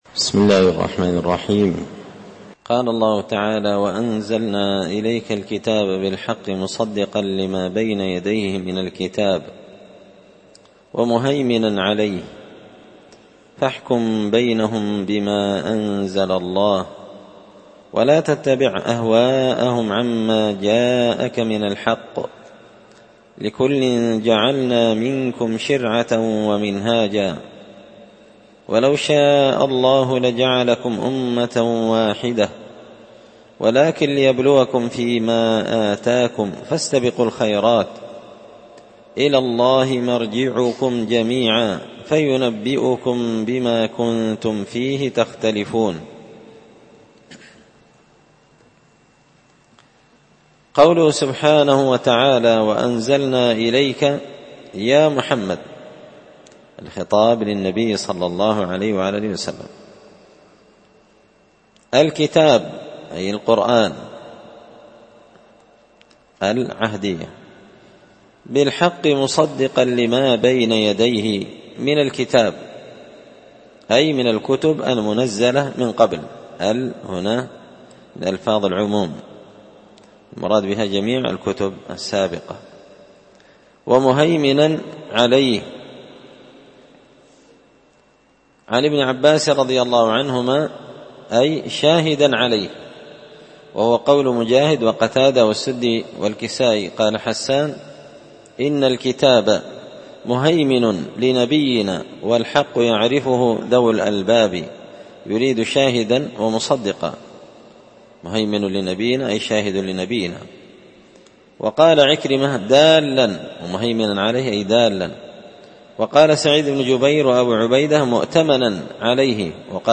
ألقيت هذه الدروس في 📓 # دار _الحديث_ السلفية _بقشن_ بالمهرة_ اليمن 🔴مسجد الفرقان